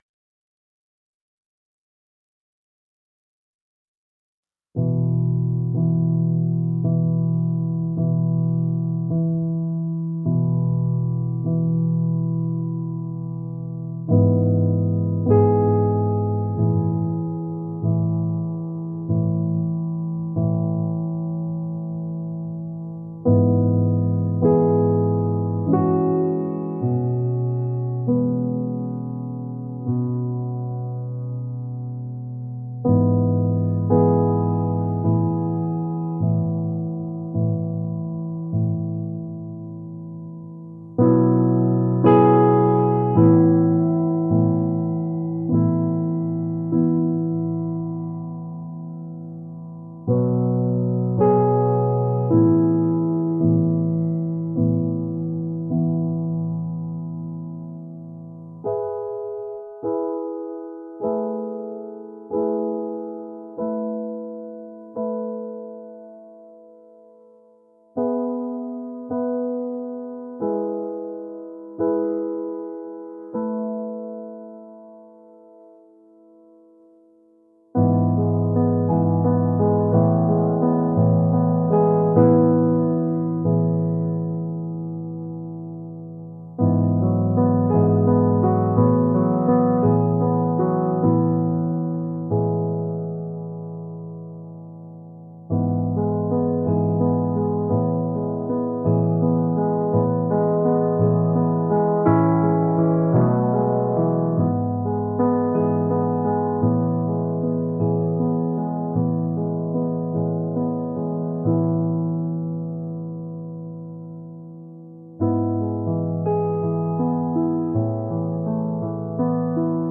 「幻想的」